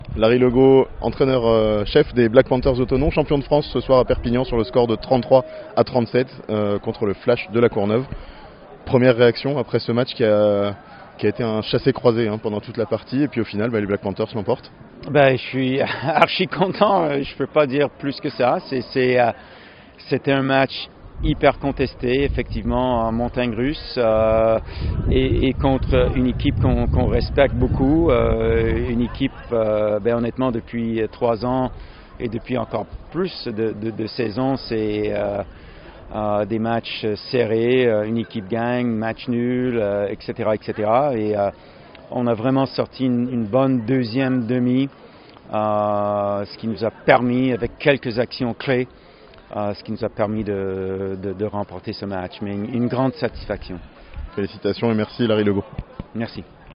Les Black Panthers conservent leur titre de champions de France (interview)